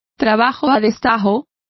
Complete with pronunciation of the translation of piecework.